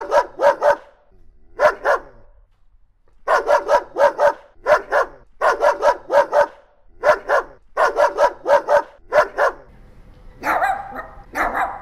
Fighting Dogs Barking